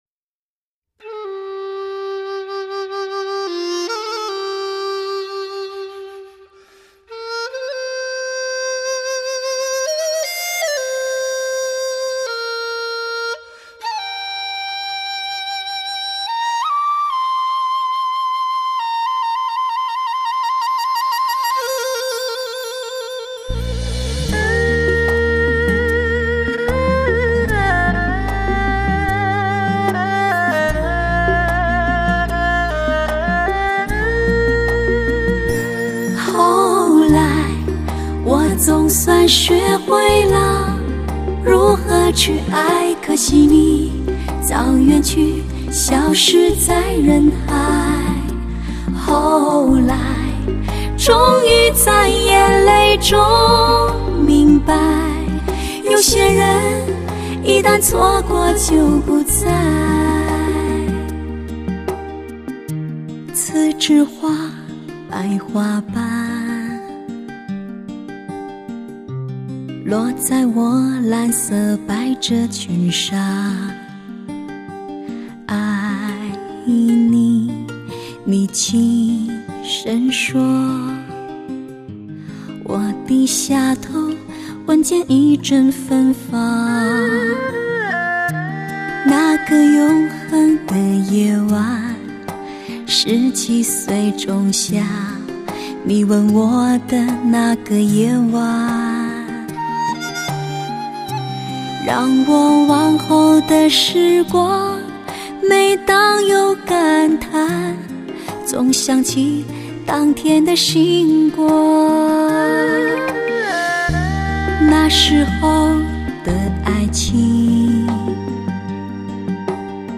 这是一张顶级发烧的CD，
聆听她的歌，她的声音有着极丰富的小资味，